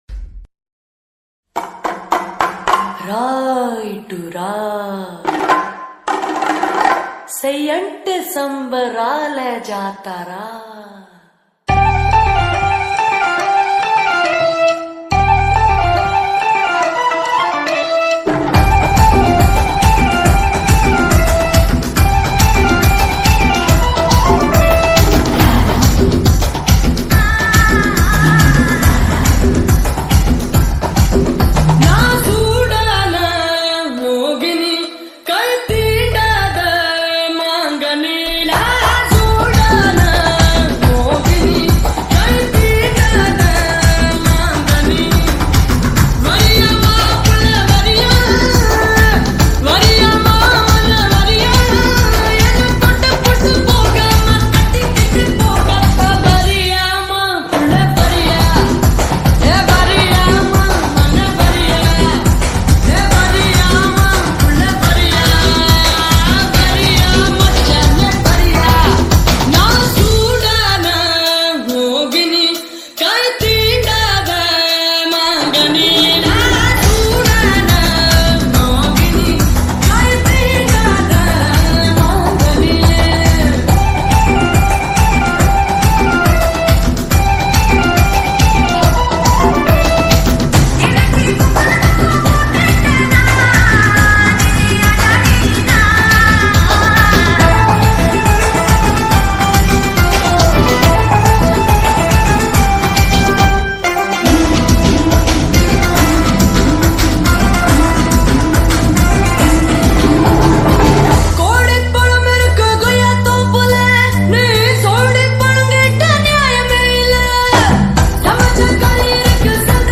Tamil 8D Songs